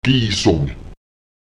Beachten Sie, dass in solchen Lehnwörtern auch die Betonung übernommen wird: